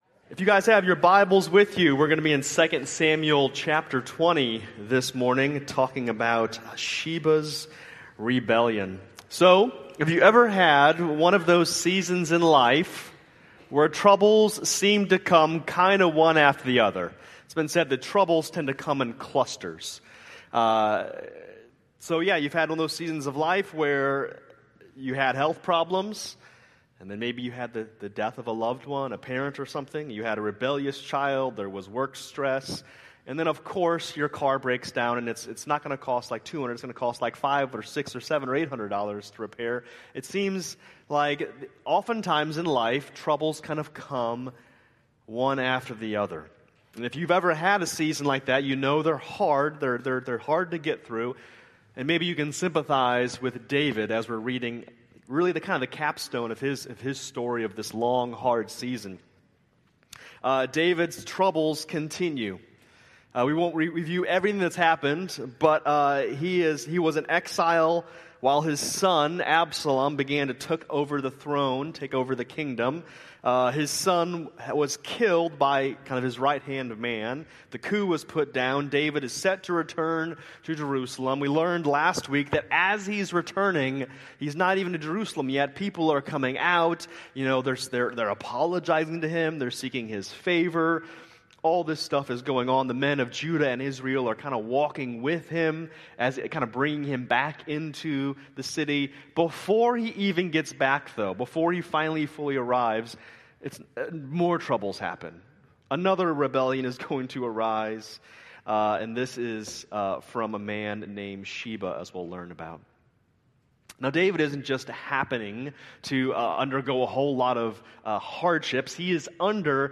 May 4, 2025 Worship Service Downloads & Resources: Order of Service: